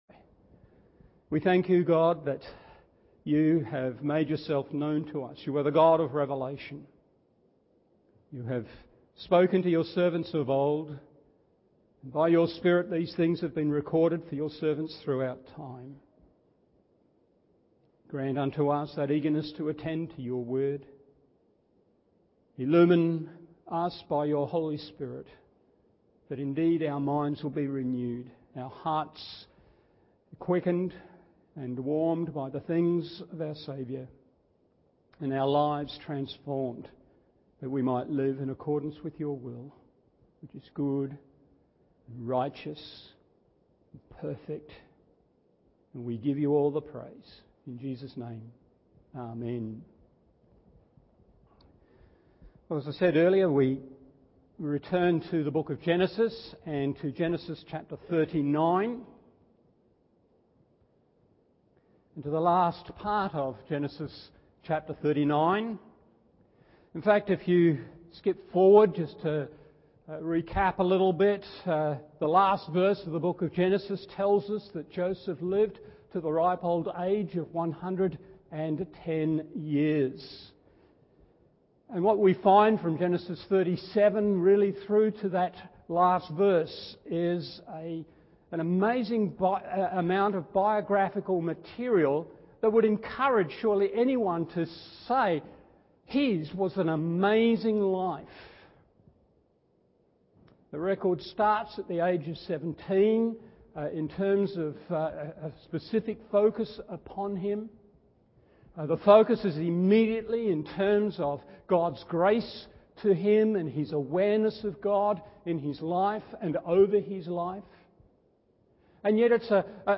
Morning Service Genesis 39:19-23 1.